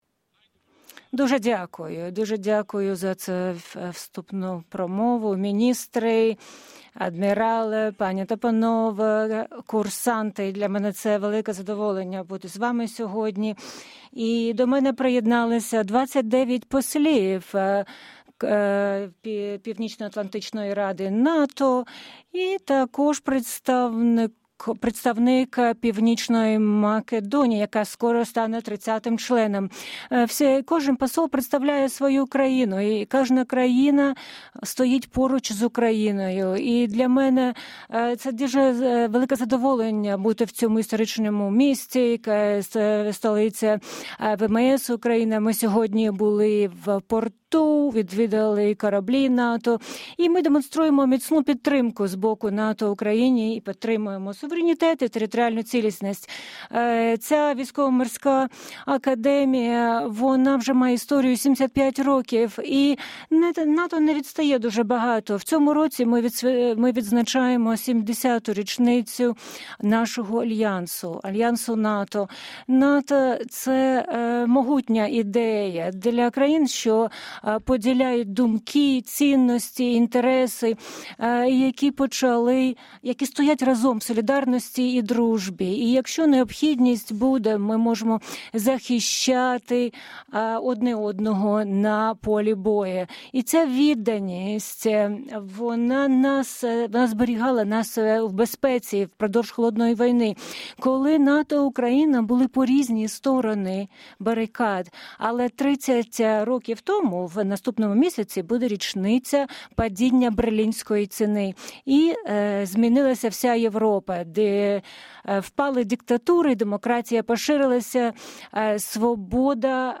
Speech by NATO Secretary General Jens Stoltenberg to cadets at the Maritime Academy in Odesa
(As delivered)